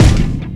Kick 28.wav